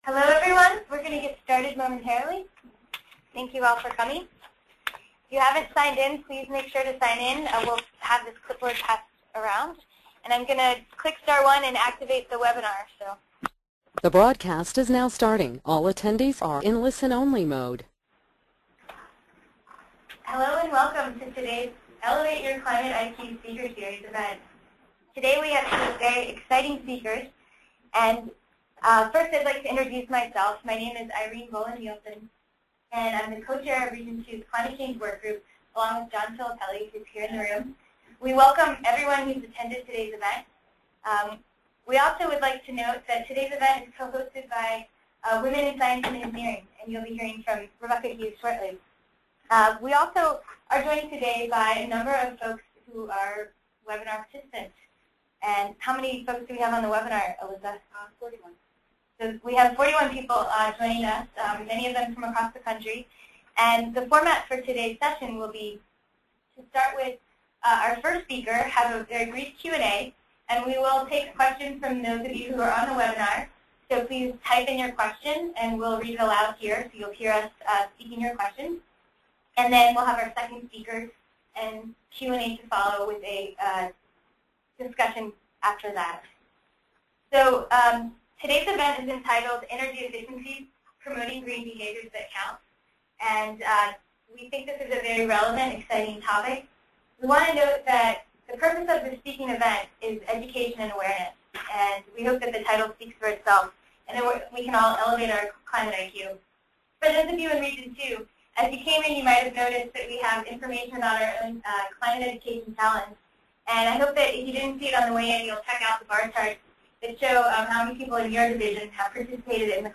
Come hear two different perspectives on the challenge of promoting energy efficient behaviors.